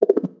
dialog-pop-out.wav